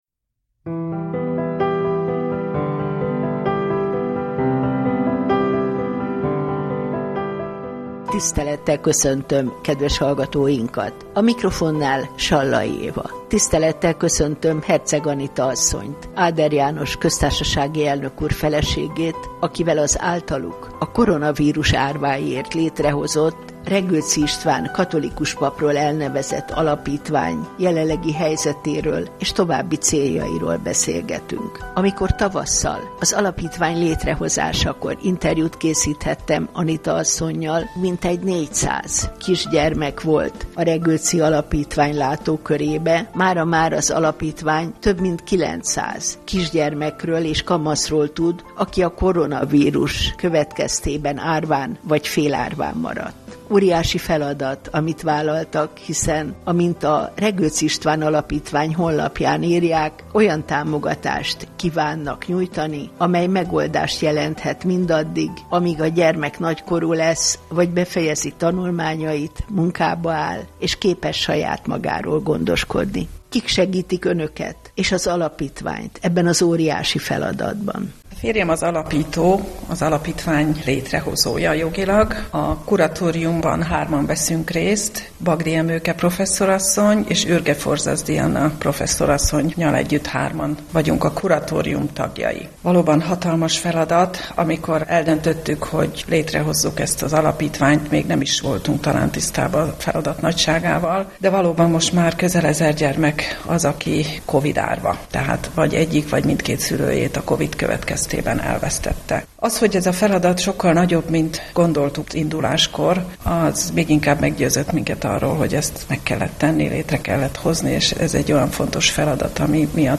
Beszélgetés a Regőczi István Alapítvány tevékenységéről a Katolikus Rádió Kerengő című műsorában